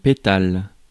Ääntäminen
Ääntäminen France: IPA: /pe.tal/ Haettu sana löytyi näillä lähdekielillä: ranska Käännöksiä ei löytynyt valitulle kohdekielelle.